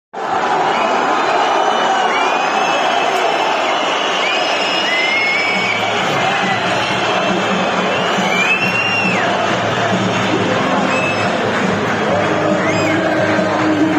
La grada despidió con pitos a la plantilla tras la debacle ante el Málaga